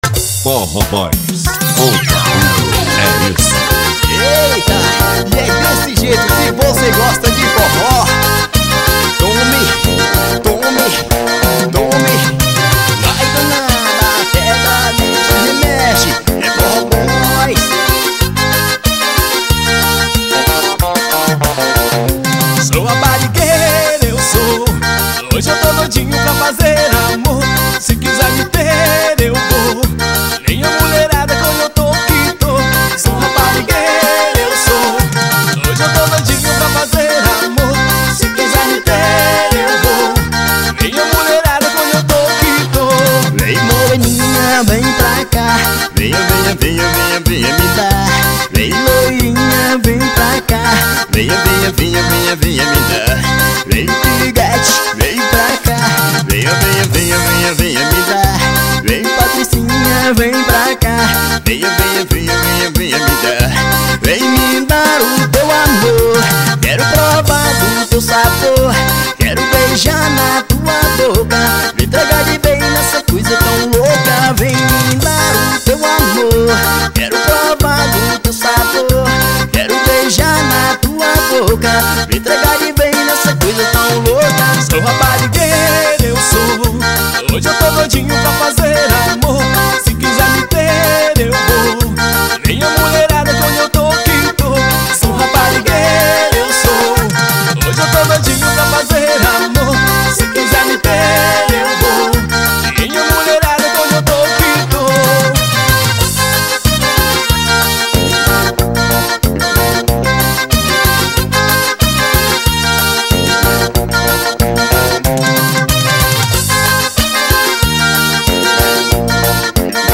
2024-05-03 07:44:36 Gênero: Forró Views